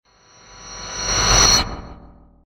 smashed glass, random sounds